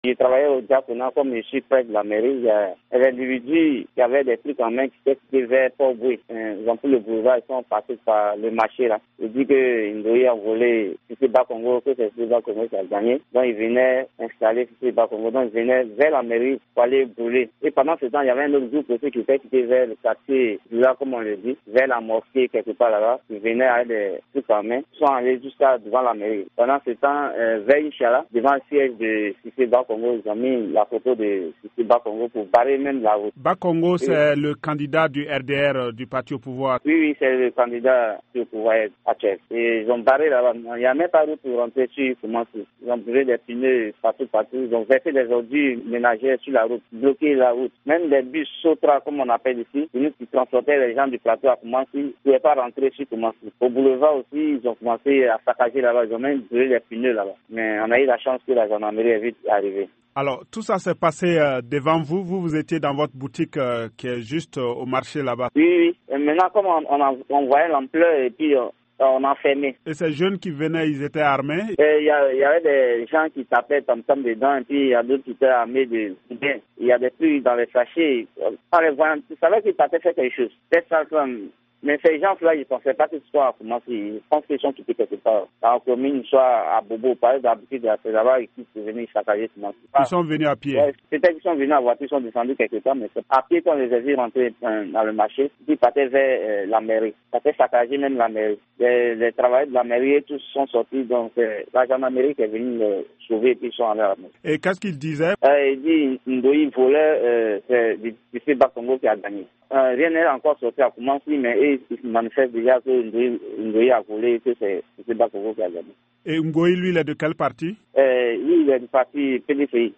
Le témoignage
joint à Abidjan